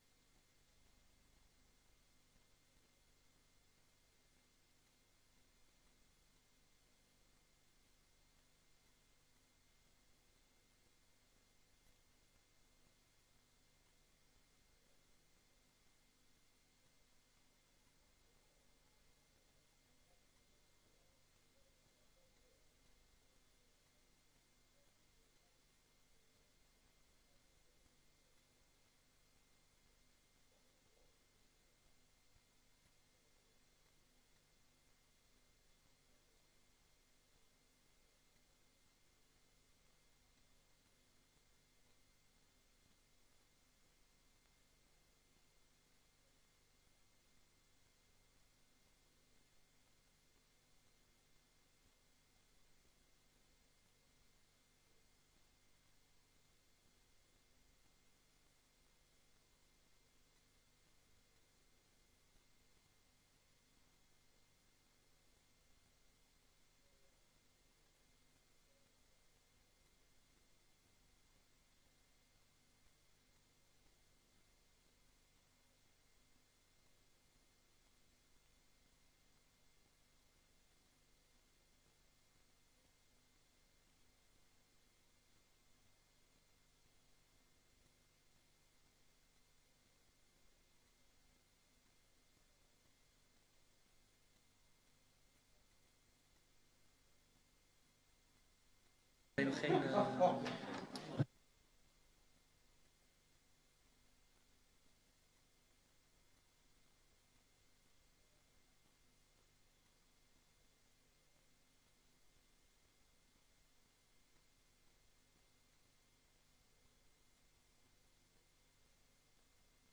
Beeldvormende bijeenkomst Papendrecht 08 mei 2025 20:00:00, Gemeente Papendrecht
Download de volledige audio van deze vergadering